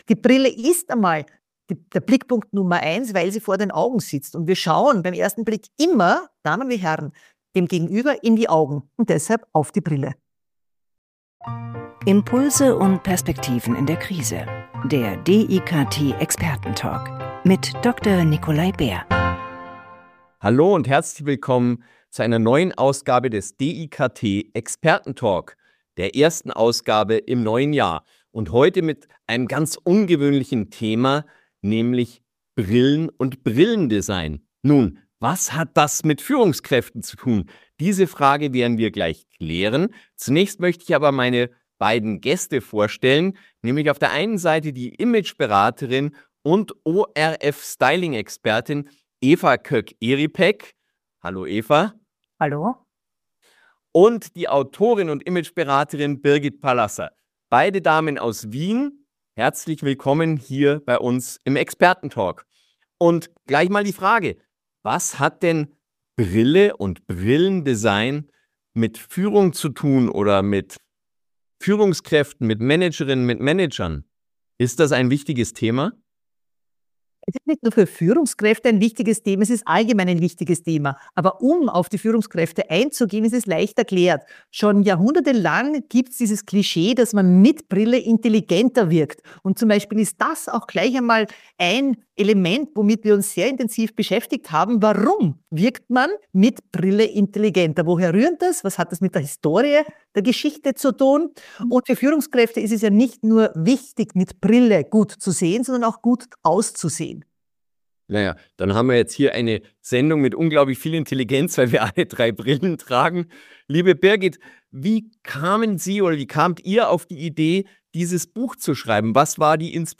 Genres: Business, Education, Management, Self-Improvement